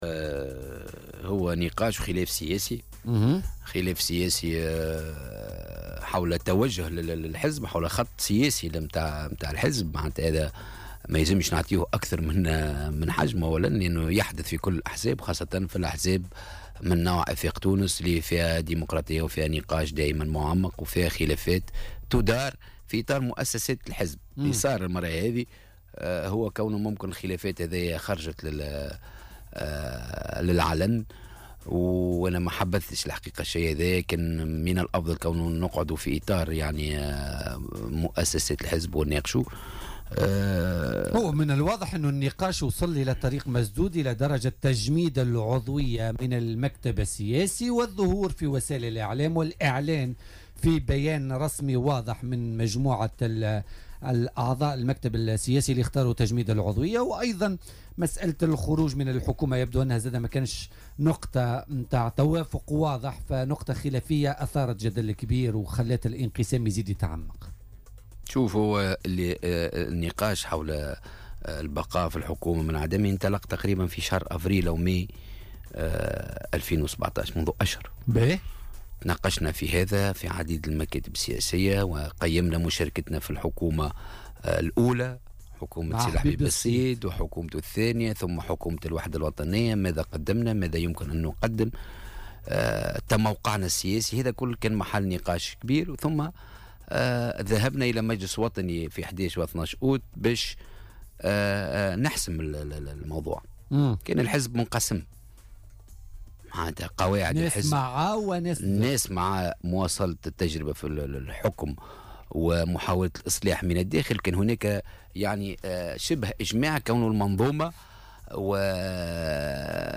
قال رئيس المكتب السياسي لحزب أفاق تونس كريم الهلالي، ضيف بوليتيكا اليوم...